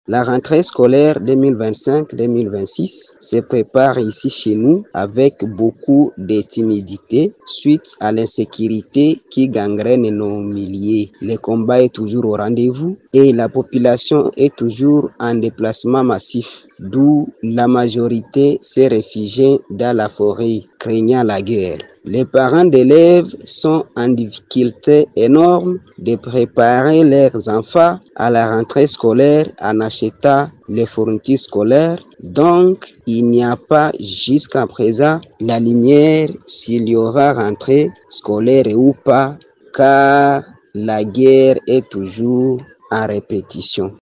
Suivez l’un d’eux au micro de Radio Okapi :